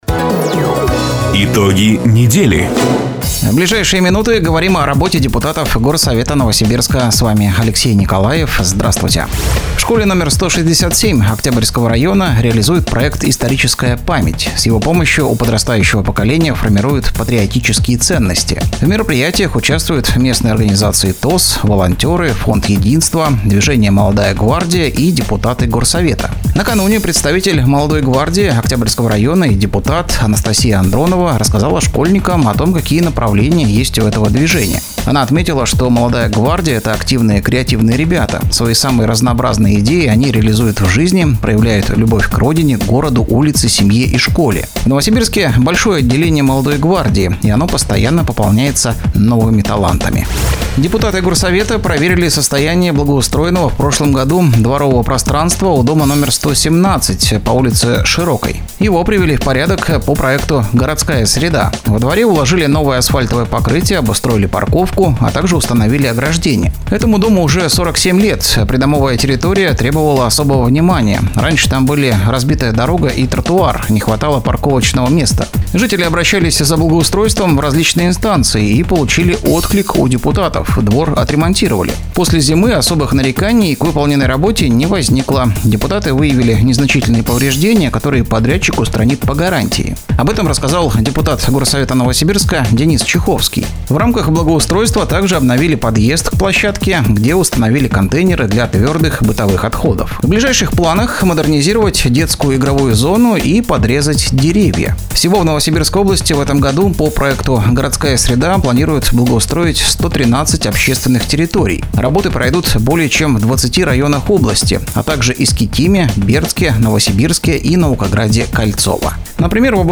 Запись программы "Итоги недели", транслированной радио "Дача" 12 апреля 2025 года